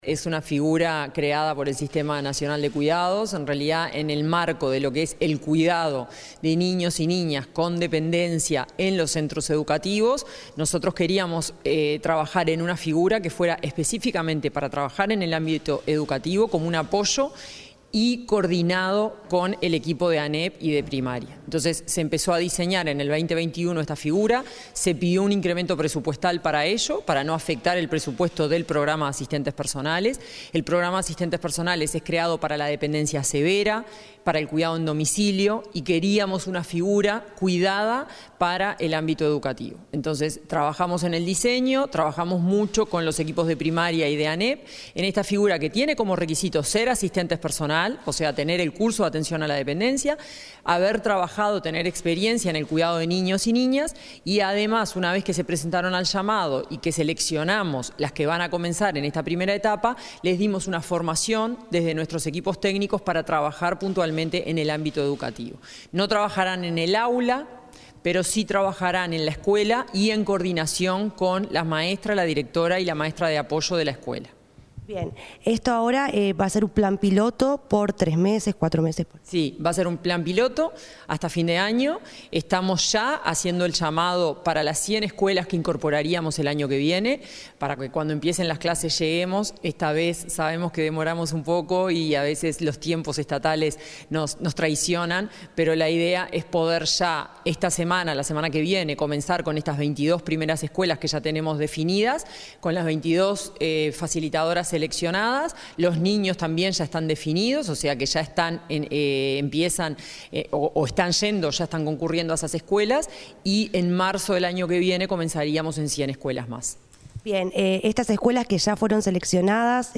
Entrevista a la directora de Cuidados del Ministerio de Desarrollo Social, Florencia Krall